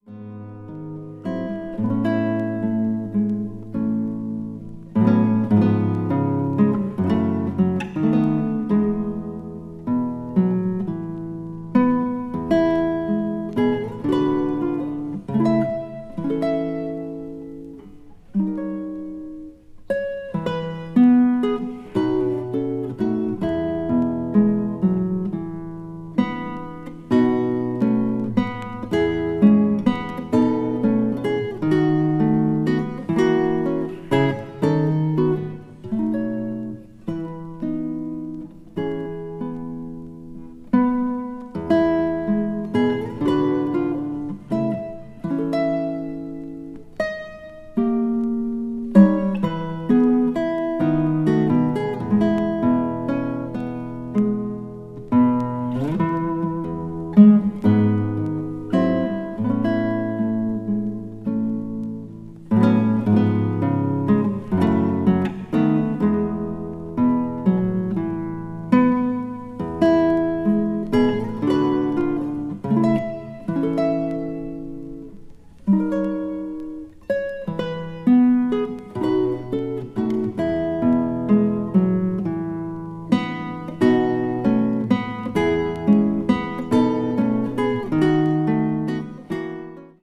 media : EX/EX(わずかにチリノイズが入る箇所あり)
まったく刺のない穏やかなサウンドがとても心地良く音場に浮遊します。